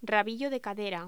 Locución: Rabillo de cadera
voz
Sonidos: Voz humana